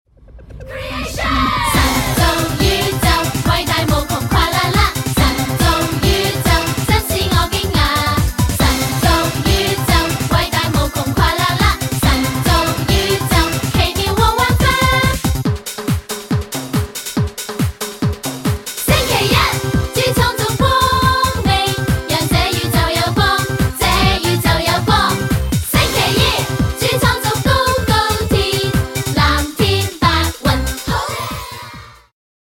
充滿動感和時代感
有伴奏音樂版本